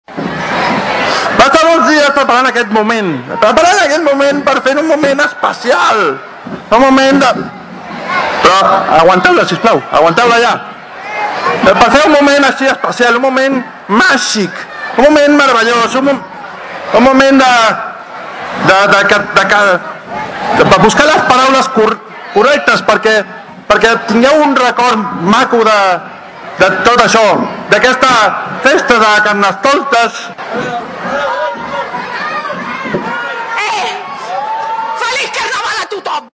Després de la rua, com és tradicional, la comissió de festes va preparar una xocolatada popular a la Plaça de l’Església, i el Rei Carnestoltes va fer el seu discurs. Com l’any passat, va estar acompanyat de tota la seva família, però va tenir “alguns problemes” amb la preparació del discurs.